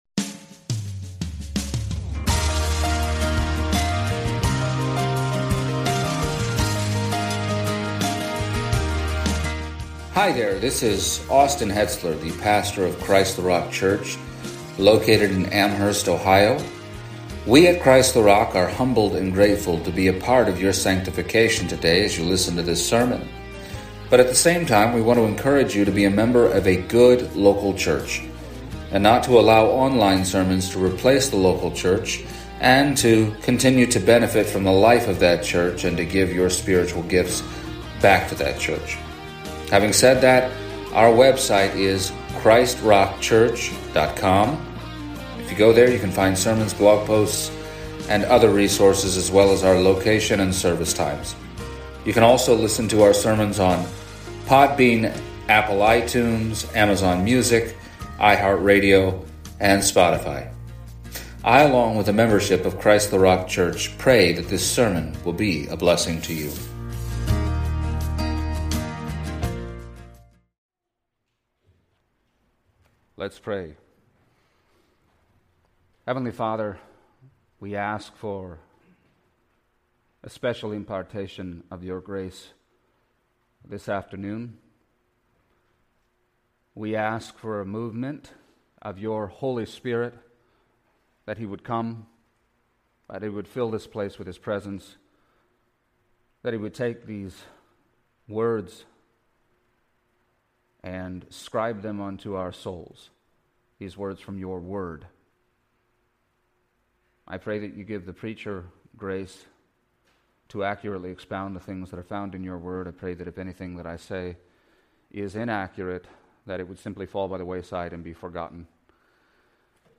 Exposition of the Book of Acts Passage: Acts 18:1-11 Service Type: Sunday Morning “His name is great.